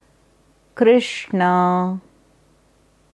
Es wird in der indischen Devanagari Schrift geschrieben कृष्णा, in der IAST Schreibweise kṛṣṇā. Hier kannst du dir anhören, wie ein Sanskrit Experte, eine Sanskritkennerin das Wort Krishnaa sagt.